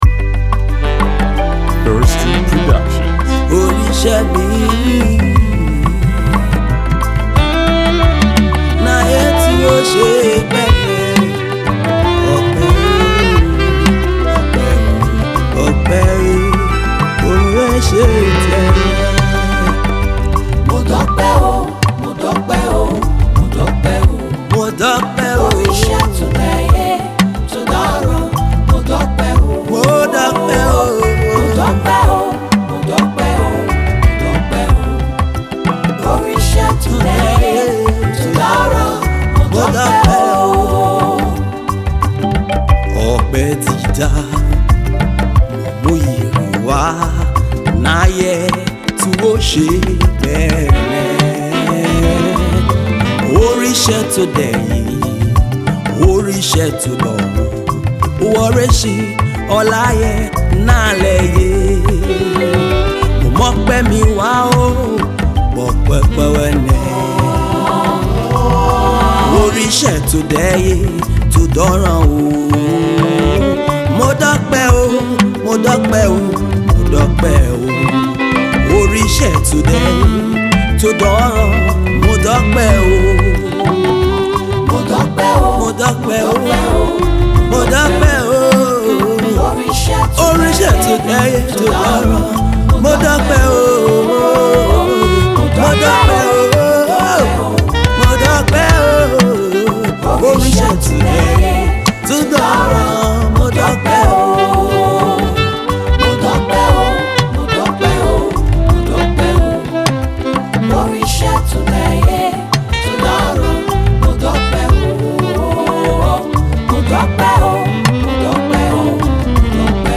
Nigerian inspirational gospel artist, songwriter & pianist